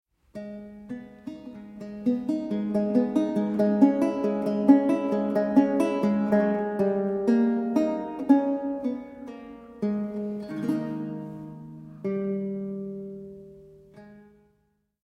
Baroque Guitar and Theorbo
Kaple Pozdvižení svatého Kříže, Nižbor 2014